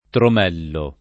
Tromello [ trom $ llo ]